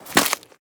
Sfx_creature_snowstalkerbaby_walk_06.ogg